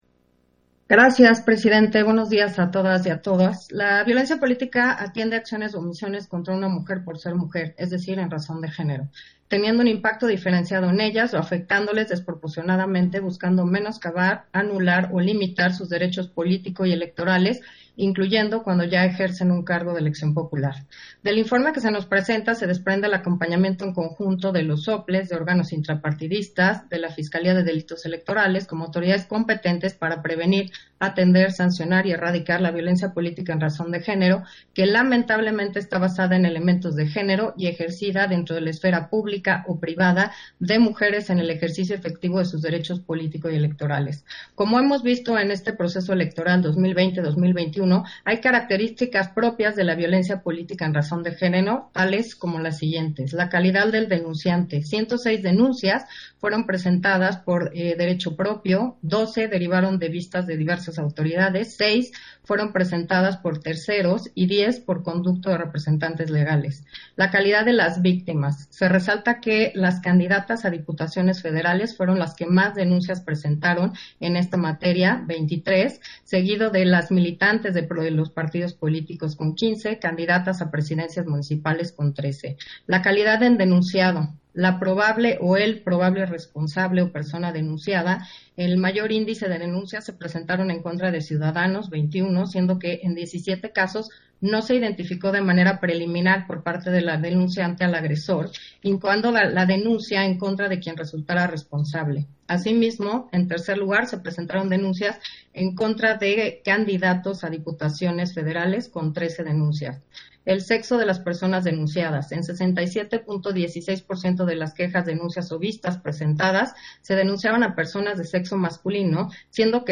Intervención de Carla Humphrey, en Sesión Ordinaria, relativo al informe presentado en cumplimiento al artículo 47 del Reglamento de Quejas y Denuncias en materia de violencia política contra las mujeres en razón de género